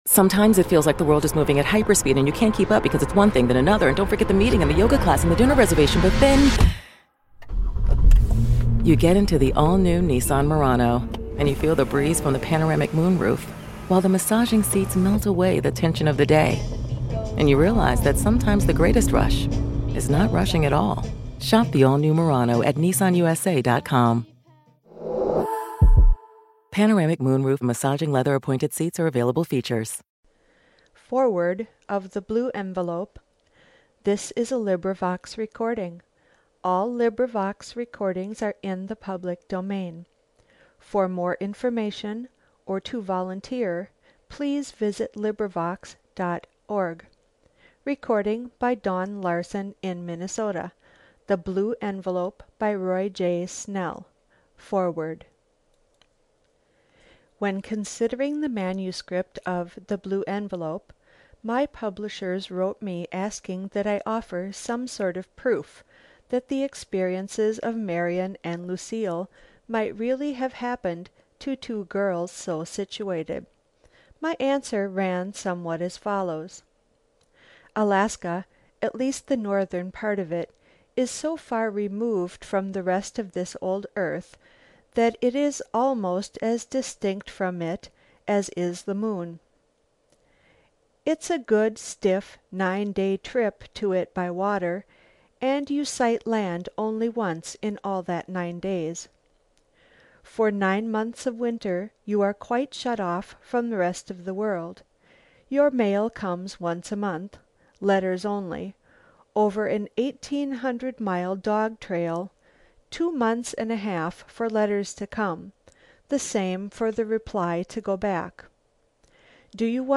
(From Wikipedia.)This is a collaborative reading.